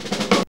JAZZ FILL 2.wav